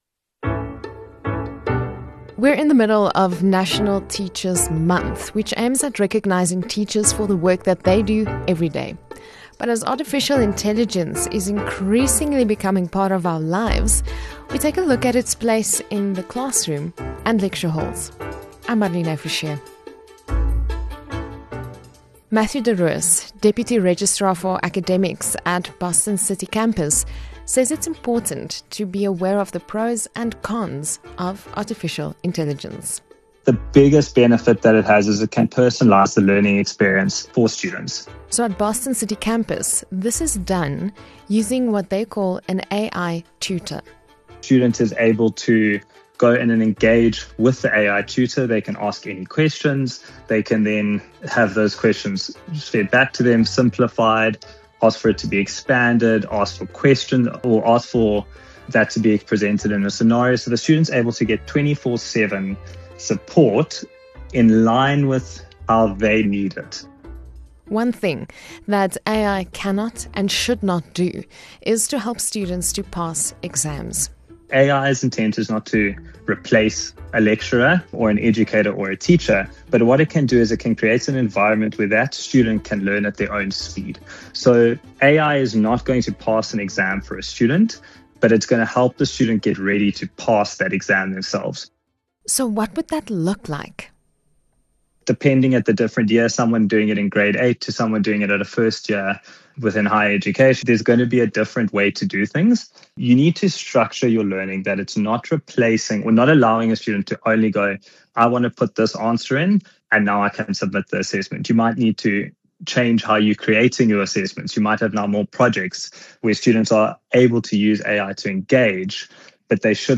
Hear the latest news interviews from the Jacaranda FM Newswatch team. The Jacaranda FM News covers local and international news of the day, providing the latest developments online and on-air.